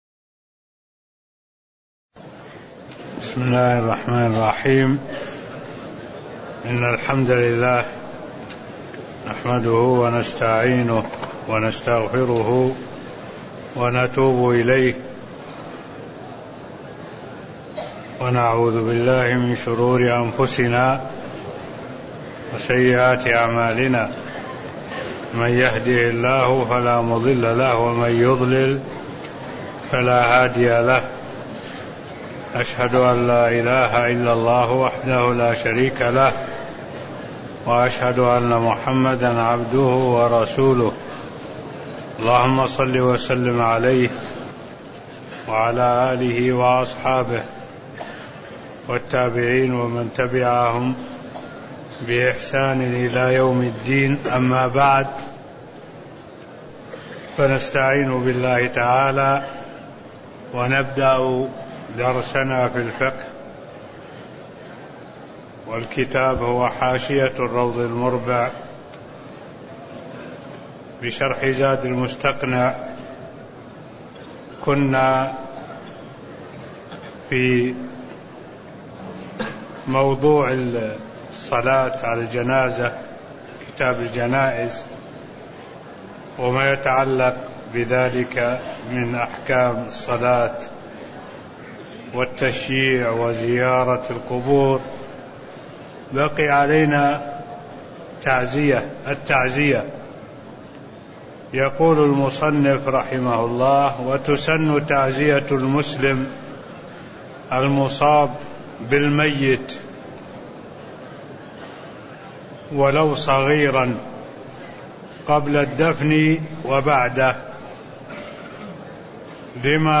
تاريخ النشر ٢٨ ذو الحجة ١٤٢٦ هـ المكان: المسجد النبوي الشيخ: معالي الشيخ الدكتور صالح بن عبد الله العبود معالي الشيخ الدكتور صالح بن عبد الله العبود التعزية (003)